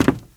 STEPS Wood, Creaky, Walk 18.wav